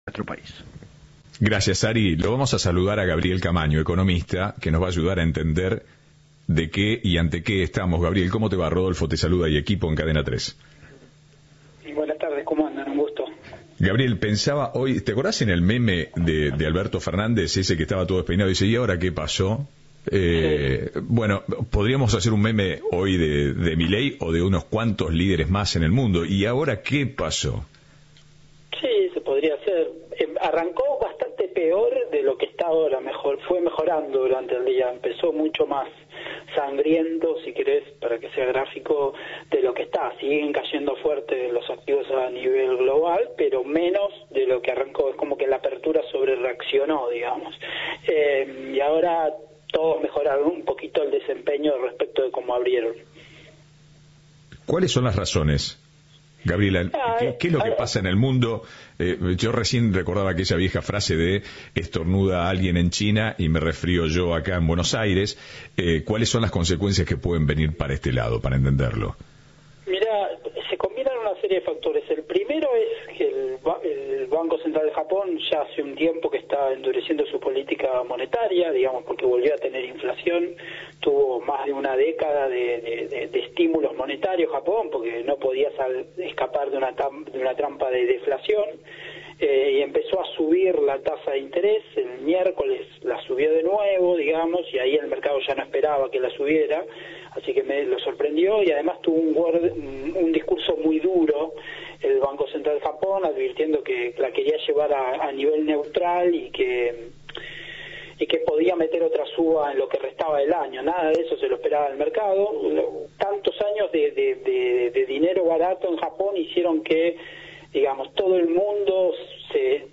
Entrevista de Ahora País